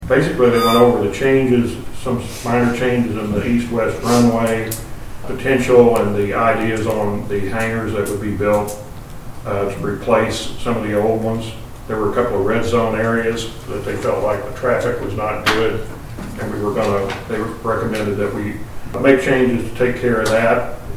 Ward III Councilman Dan Brandt gave the Storm Water Tip of the Month during the meeting of the Marshall City Council on Monday, June 7.